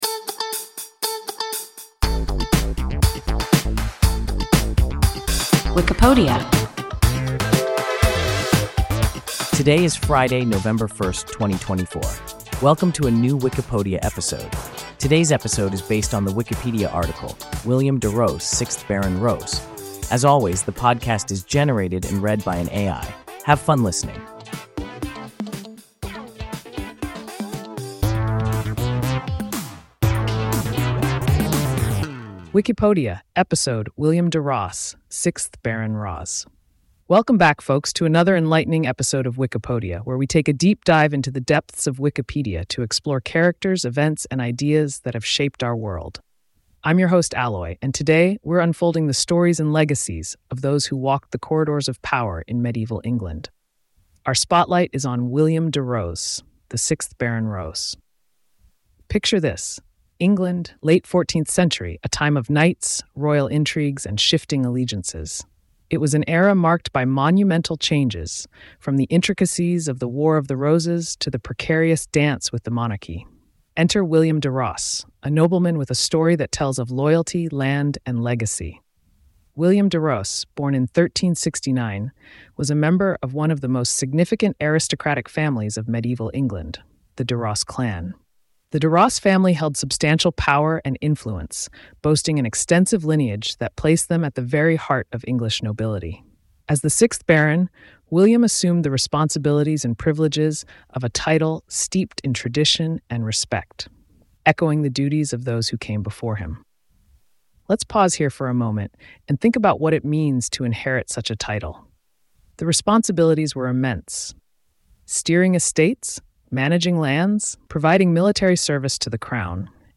William de Ros, 6th Baron Ros – WIKIPODIA – ein KI Podcast